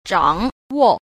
3. 掌握 – zhǎngwò – chưởng ác (giữ lại, nắm chặt)